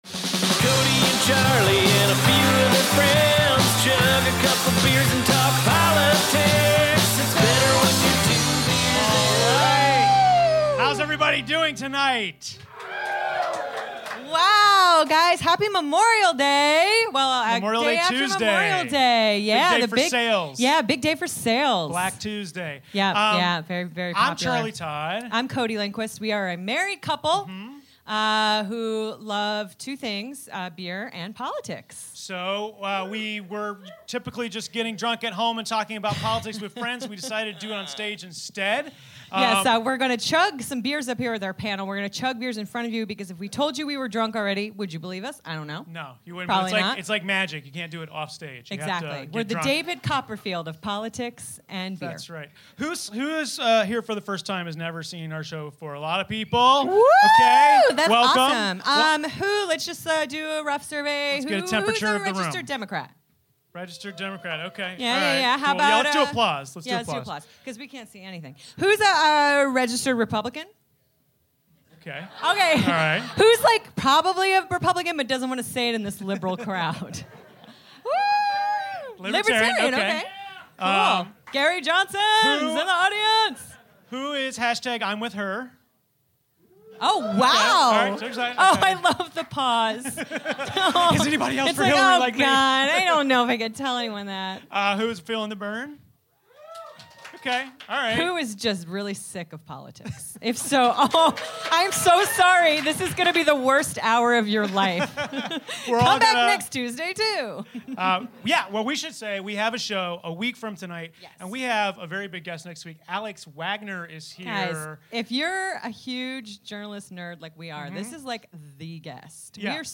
We chug and chat with writers from John Oliver, Sam Bee, and a NY Daily News editorial board member. Plus Anthony Atamanuik's world famous Trump impression and even more outrageous self. Recorded live from the UCB Theatre East Village on May 31, 2016